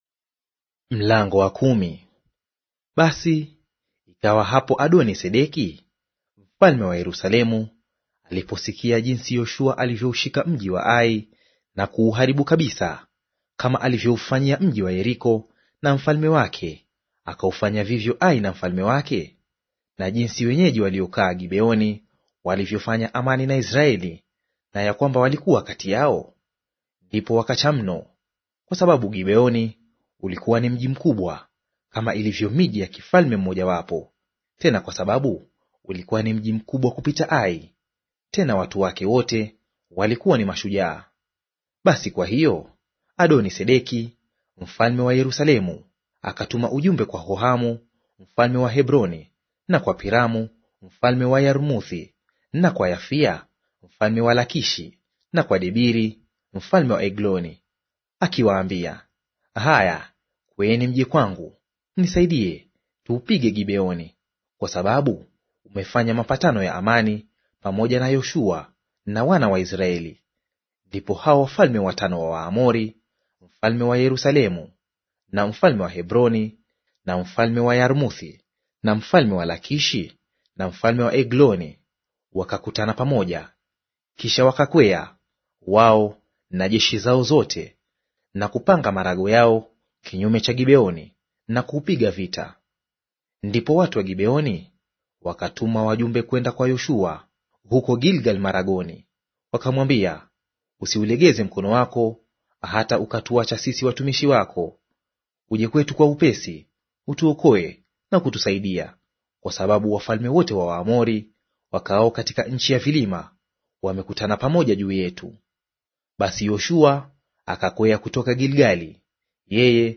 Audio reading of Yoshua Chapter 10 in Swahili